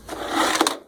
cordinsert.ogg